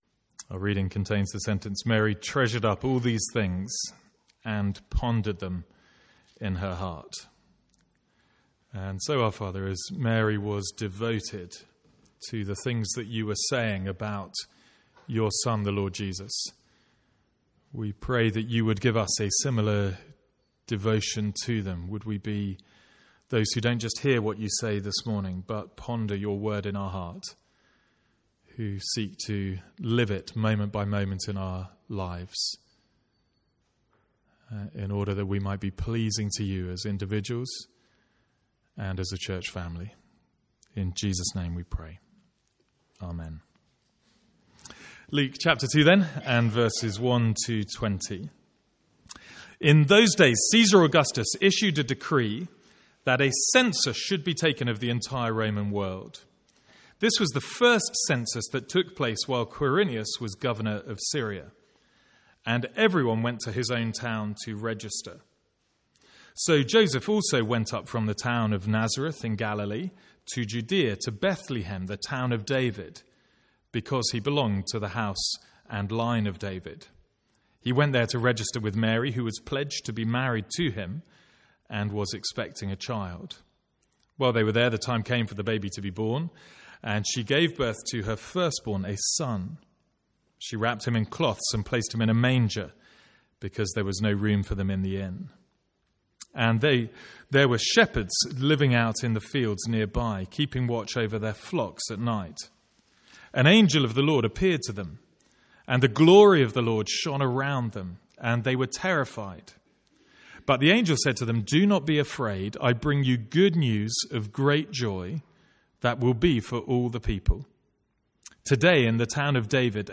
Sermons | St Andrews Free Church
From the Sunday morning series in Luke.